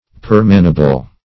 Permanable \Per"ma*na*ble\